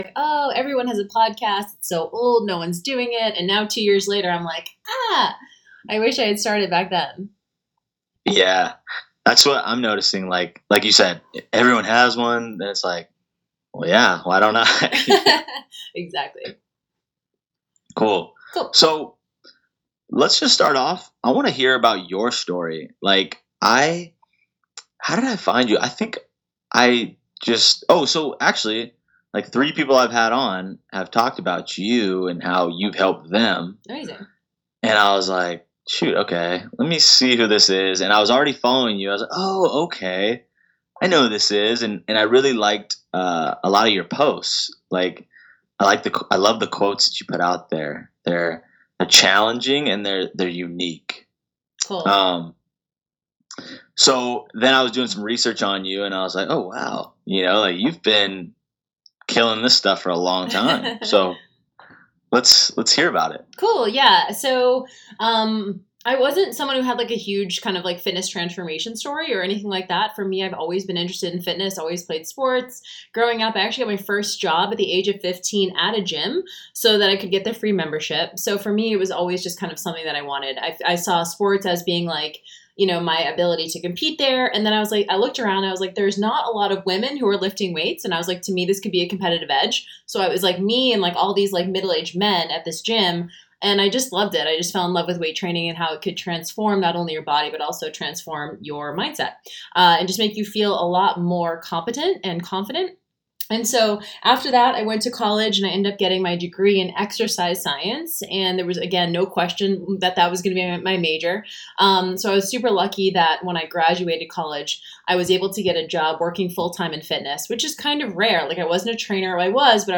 First, you can listen to the whole interview HERE —I discussed my story of food obsession, competition lifestyle, and how I started the business at JillFit. I shared what I believe “mindset” to be and the exact moment that I started to change mine.